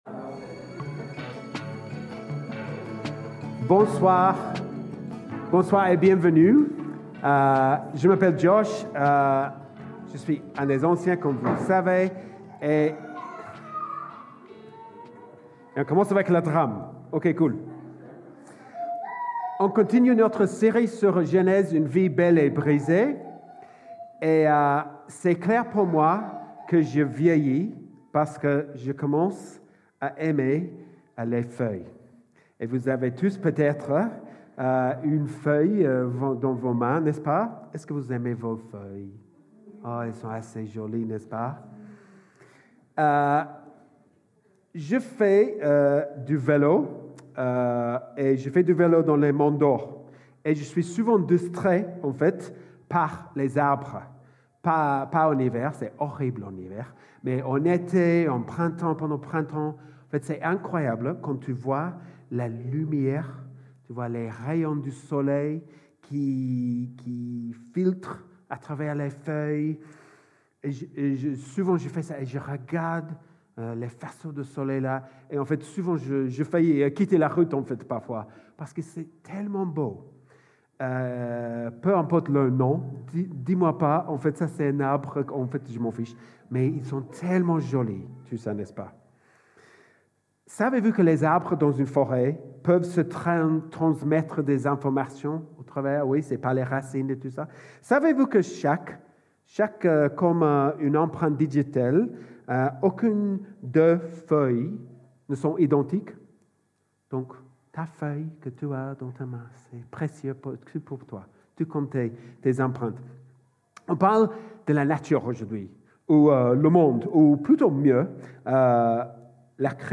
Predication1-19.mp3